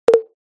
「ぽっ、ぽっ」という音を特徴とした楽しくて軽快な通知音です。